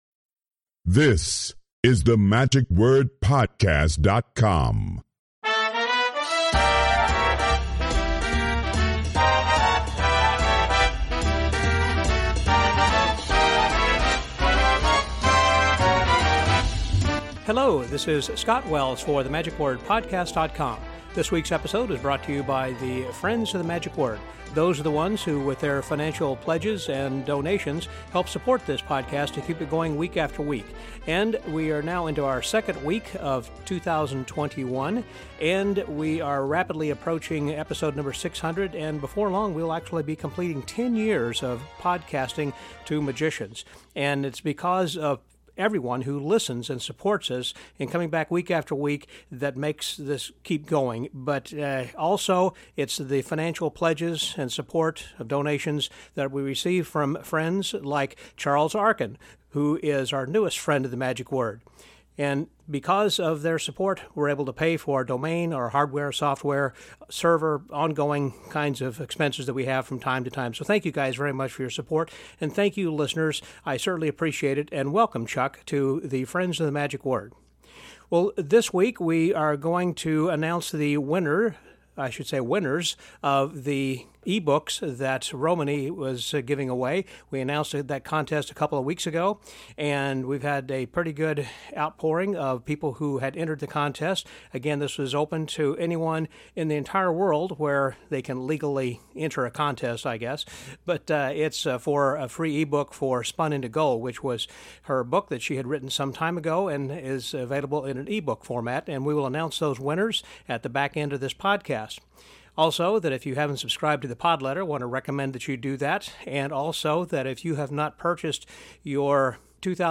During a recent trip from Houston, Texas, to Michigan, I had the opportunity to sit down for an in-depth conversation with one of magic’s creative thinkers